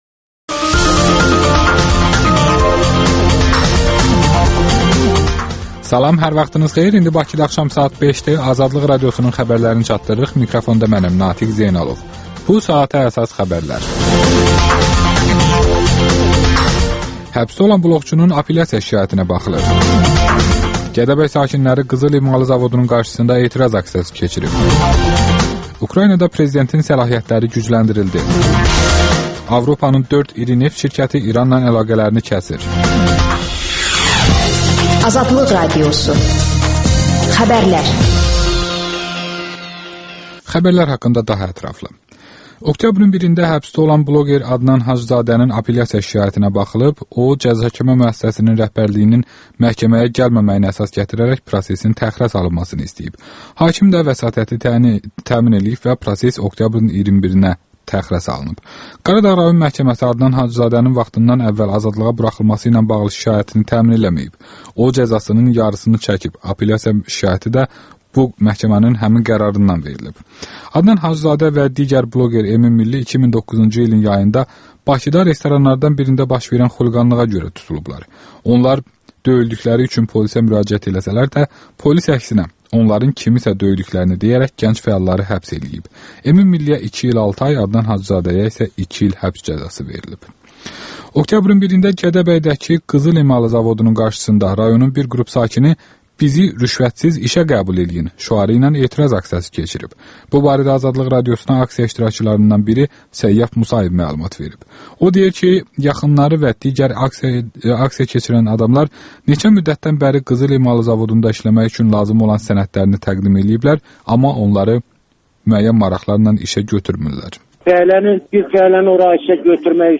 İşdən sonra - xalq artisti Flora Kərimova ilə söhbət